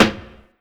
• Tight Low End Snare One Shot A Key 63.wav
Royality free snare drum sound tuned to the A note.
tight-low-end-snare-one-shot-a-key-63-EXg.wav